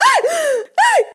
Mujer asustada
susto
mujer
Sonidos: Acciones humanas
Sonidos: Voz humana